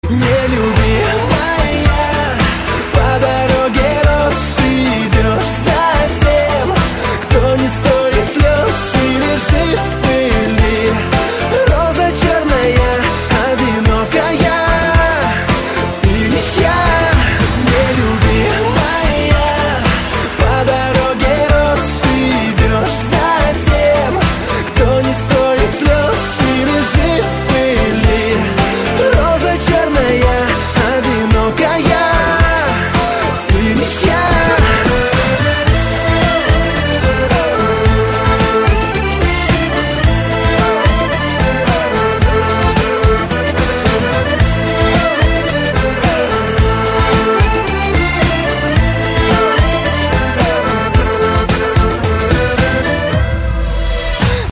• Качество: 128, Stereo
поп
мужской вокал
громкие
красивая мелодия
аккордеон